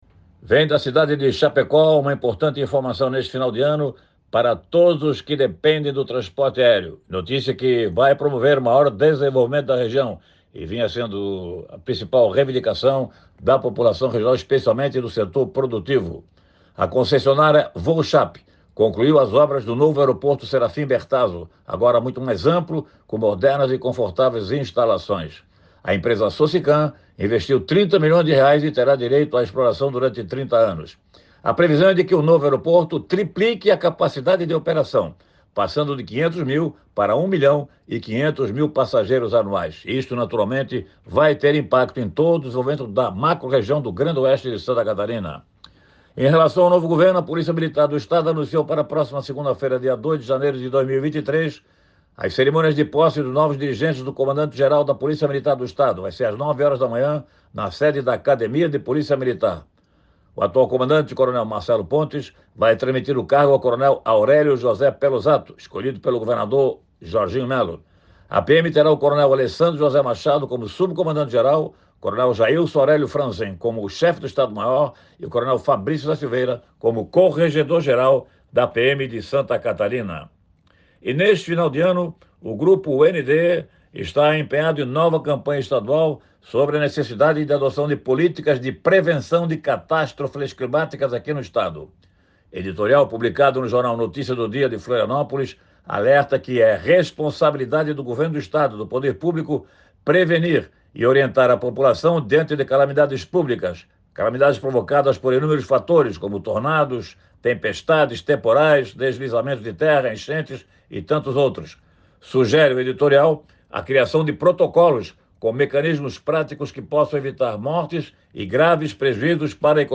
Jornalista comenta que, com esta obra, vários setores produtivos da região serão beneficiados. Outro destaque no comentário é sobre a troca de comando da PMSC
Confira o comentário desta quarta-feira: